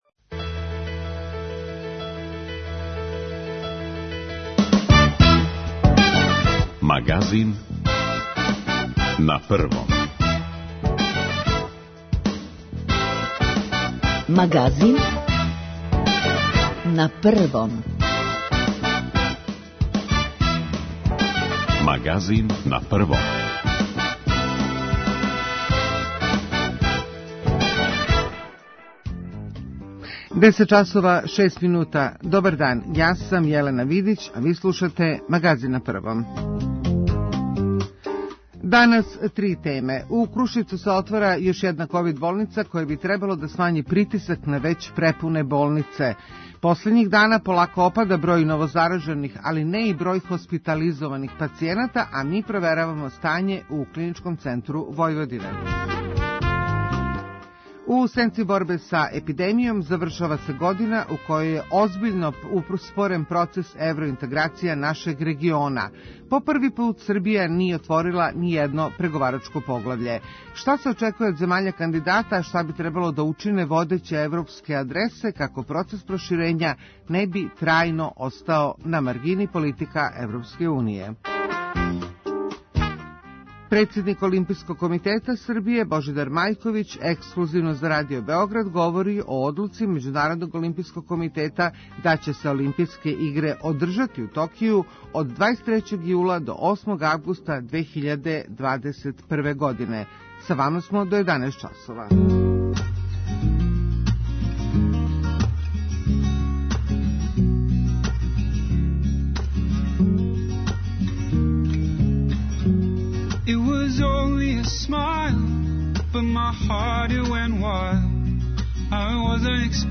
Председник Олимпијског комитета Србије Божидар Маљковић ексклузивно за Радио Београд говори о одлуци Међународног олимпијског комитета да ће се Олимпијске игре одржати у Токију, од 23. јула до 8. августа 2021. године.